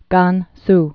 (gäns) also Kan·su (käns, gän-)